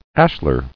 [ash·lar]